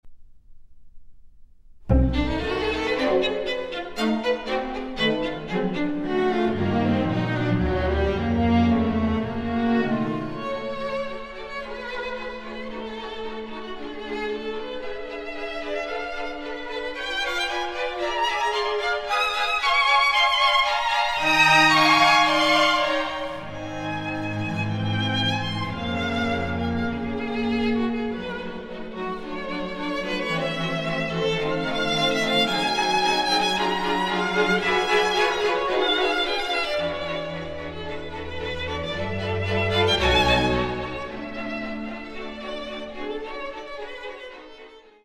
violin
Concerto for Violin and Bass Clarinet